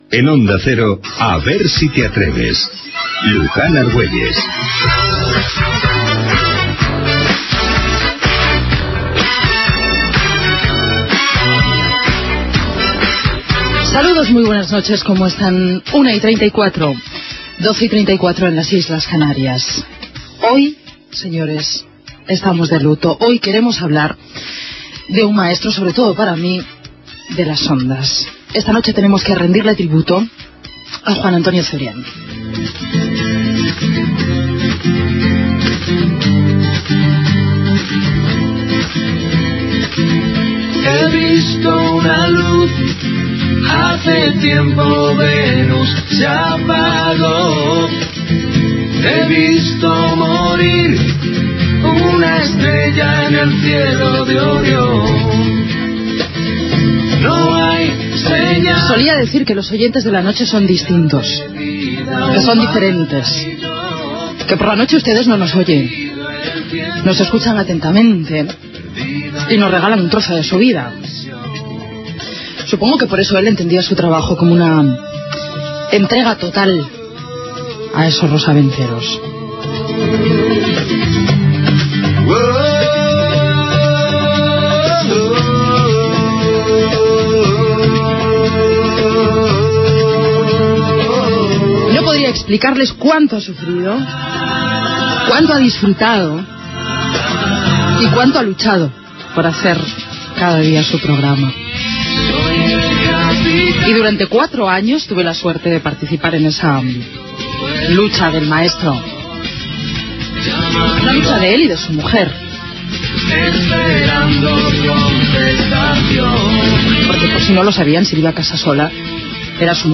Indicatiu del programa, hora, salutacio, tribut al presentador d'Onda Cero, Juan Antonio Cebrián, que havia mort.
Entreteniment
FM